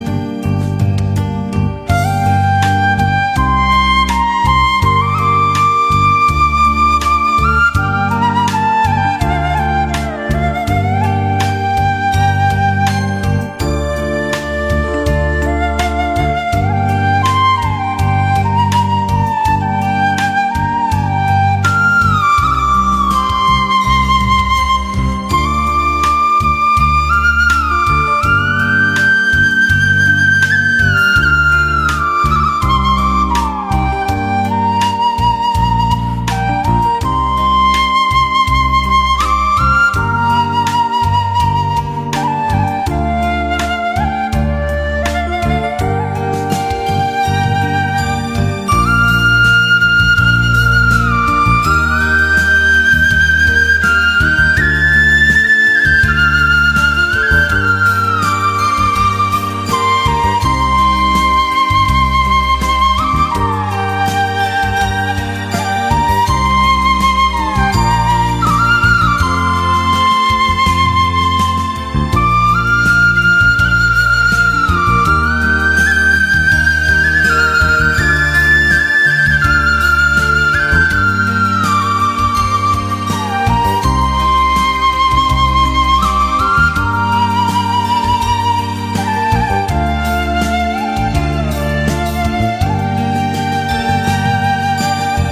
giai điệu hoài niệm và thanh thoát.
bản không lời chất lượng cao